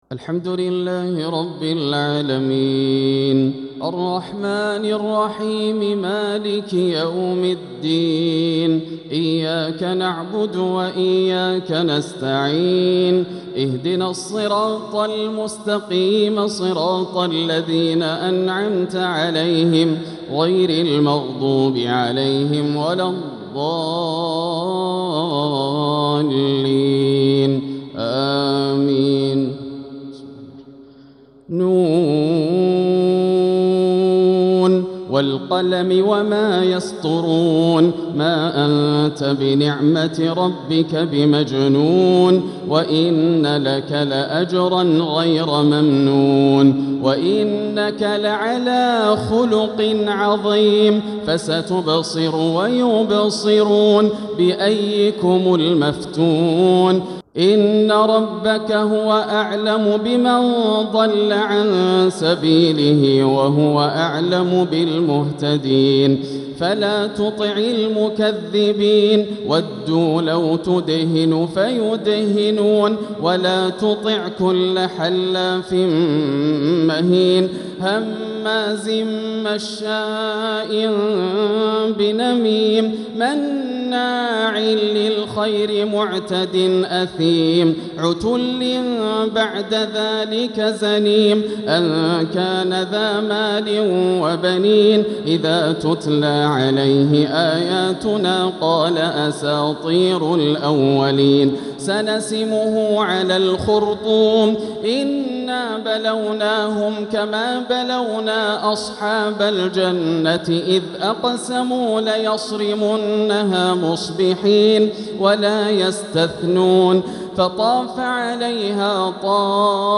ليلة قرآنية مهيبة تألق فيها الشيخ ياسر الدوسري من سورة القلم إلى سورة المعارج | ليلة 28 رمضان 1446 > الليالي الكاملة > رمضان 1446 هـ > التراويح - تلاوات ياسر الدوسري